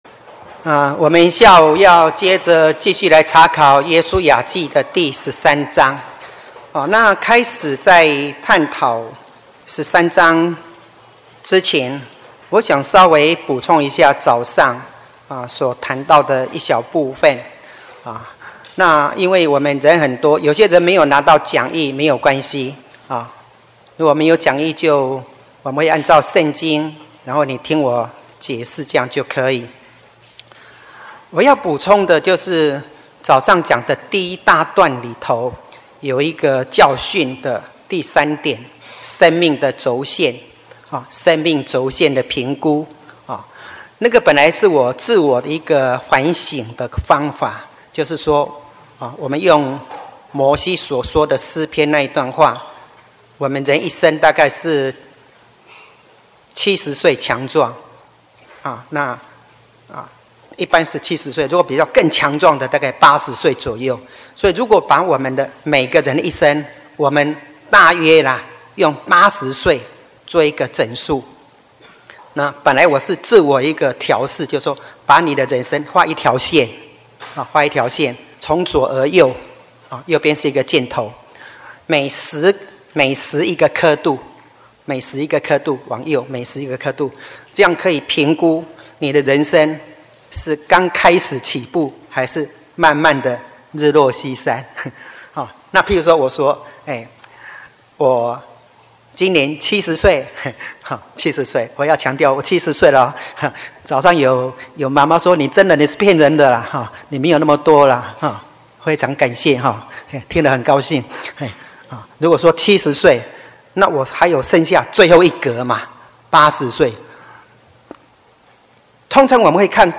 聖經講座:約書亞記(十五)-未得之地 2-講道錄音